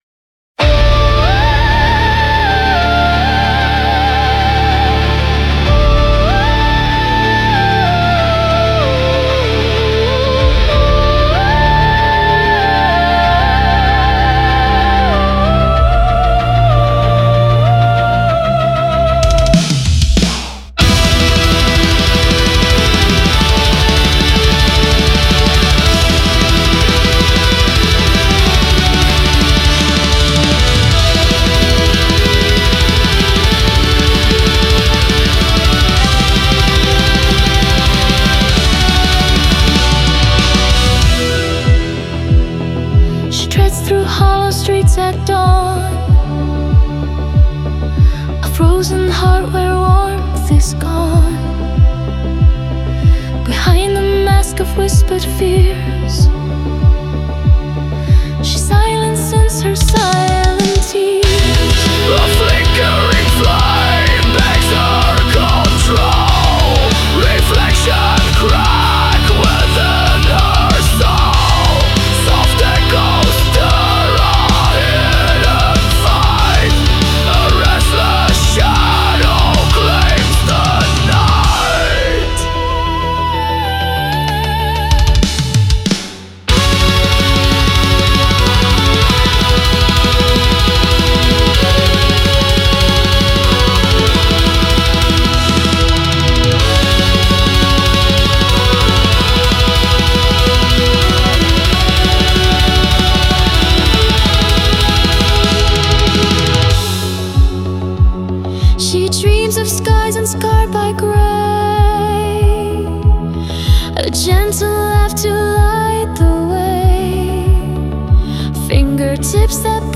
Symphonic Metal
Verse: guttural male growls convey raw ferocity.